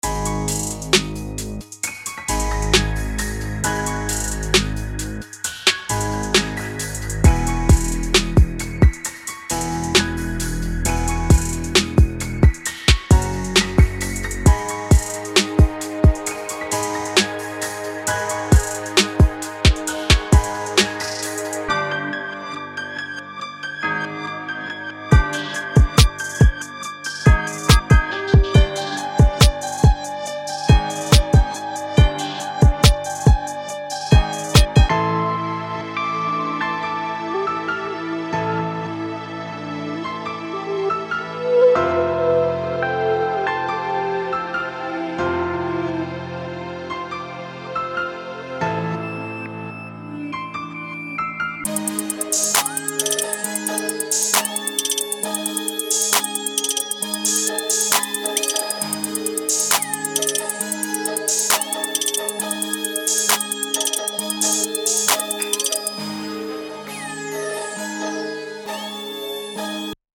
cozy, head-nod vibe